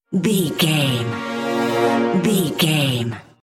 Horror Thriller Build Up.
Aeolian/Minor
scary
tension
ominous
dark
suspense
eerie
violin
cello
double bass
drums
percussion
viola
orchestral instruments